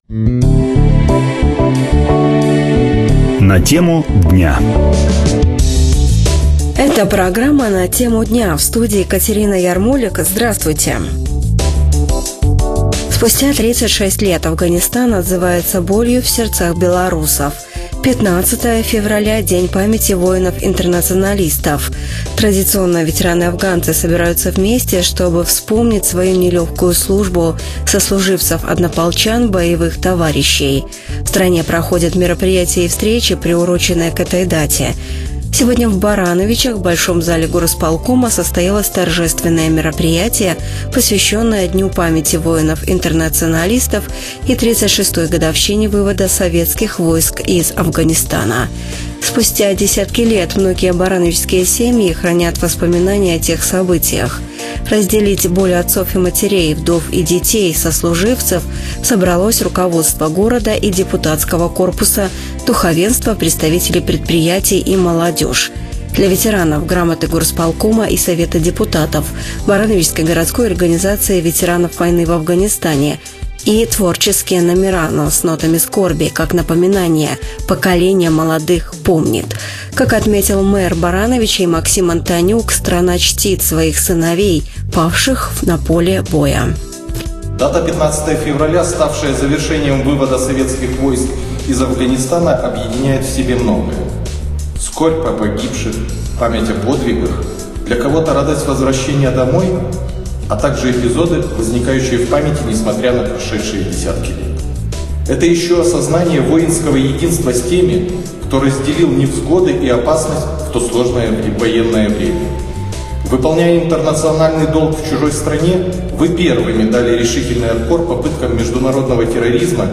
В Барановичах в большом зале горисполкома состоялось торжественное мероприятие, посвящённое Дню памяти воинов-интернационалистов и 36-й годовщине вывода Советских войск из Афганистана.